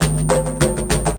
Percussion 03.wav